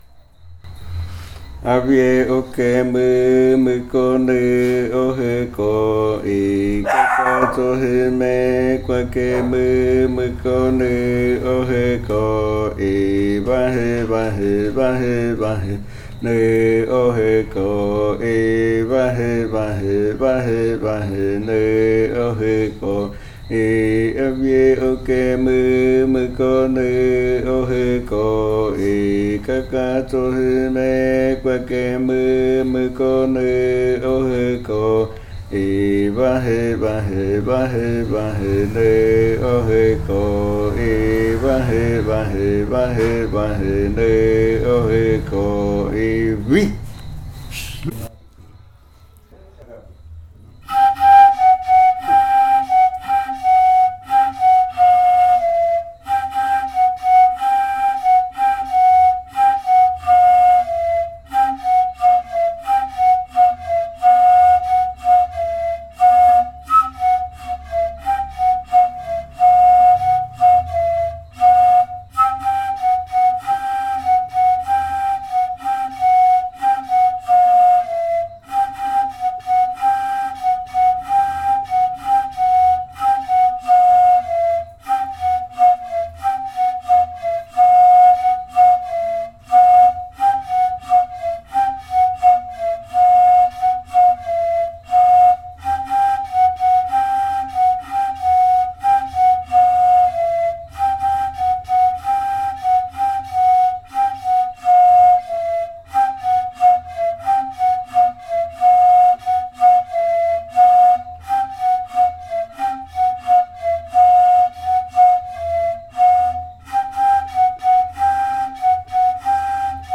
Canto de avispa
Leticia, Amazonas, (Colombia)
Canto Múúmukó llijchúju 'Canto de la avispa' (lengua bora) e interpretación del canto en pares de reribakui.
Múúmukó llijchúju chant (chant of the wasp, Bora language) and performance of the chant in reribakui flutes.
This recording is part of a collection resulting from the Kaɨ Komuiya Uai (Leticia) dance group's own research on pan flutes and fakariya chants.